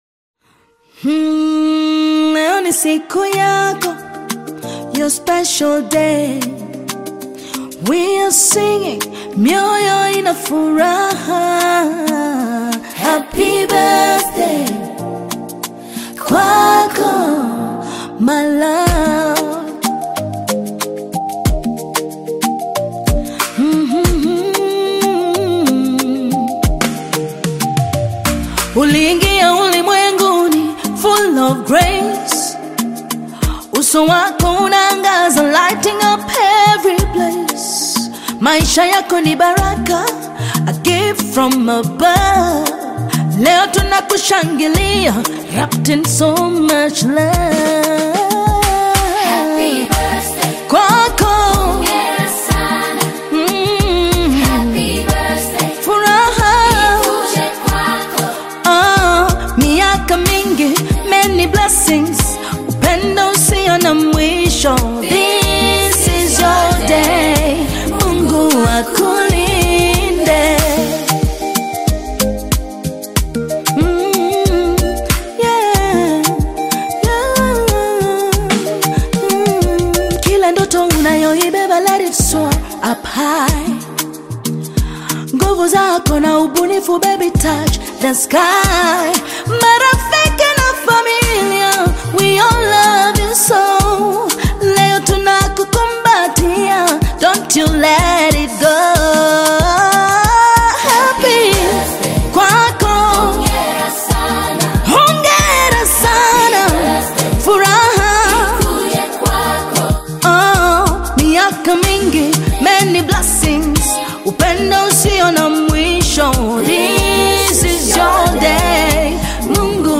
Latest Tanzania R&B Dancehall Single (2026)
Genre: R&B Dancehall